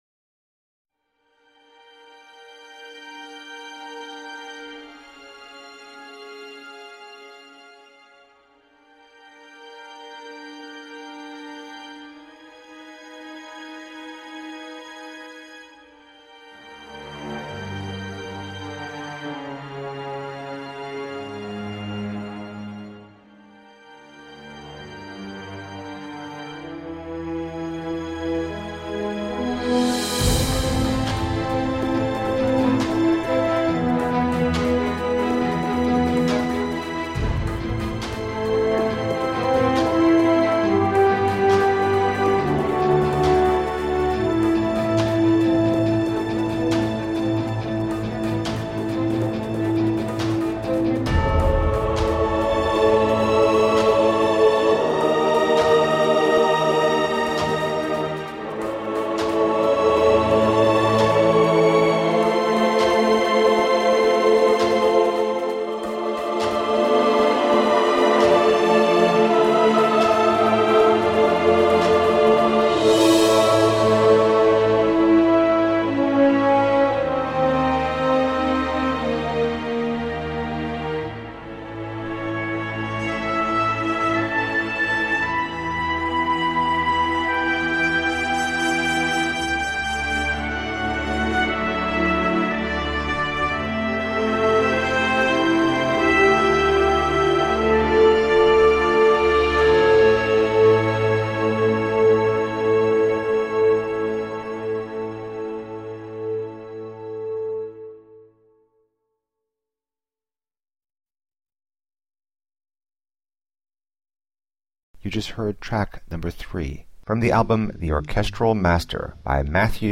Masterfully composed orchestral and electronic film scores.